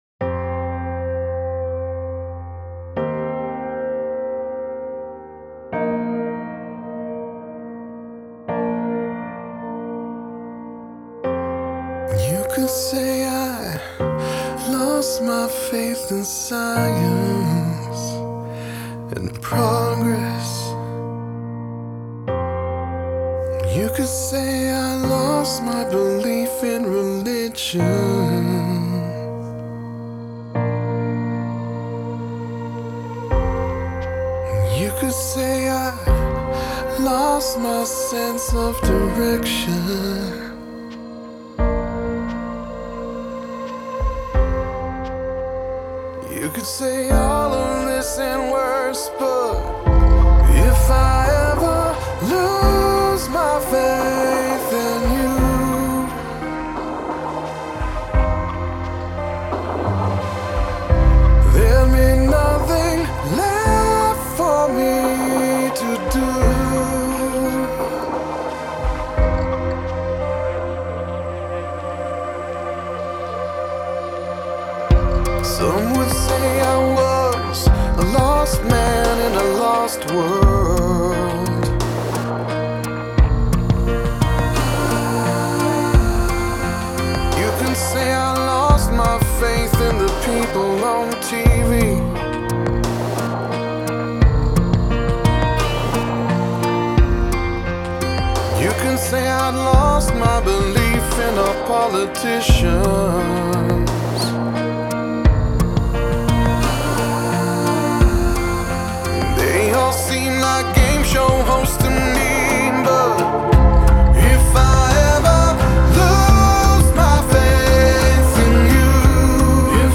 کاور آهنگ